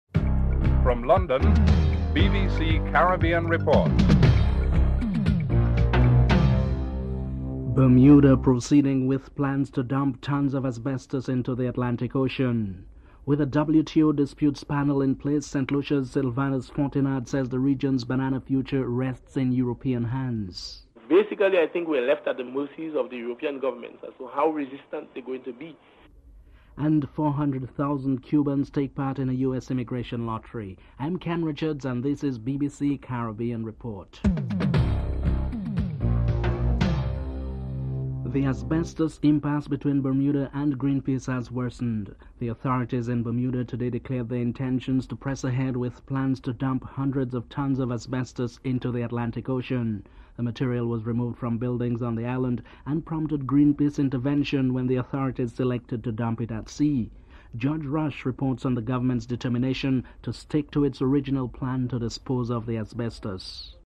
1. Headlines (00:00-00:37)
3. Vincentian Prime Minister Sir James Mitchell wants the Organisation of Eastern Caribbean States to be granted membership in the Inter-American Bank. The IDB is making a thirty-eight million dollar loan available for OECS projects through the Caribbean Development Bank. Prime Minister Sir James Mitchell is interviewed (04:31-05:42)